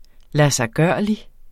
Udtale [ lasɑjˈgɶɐ̯ˀli ]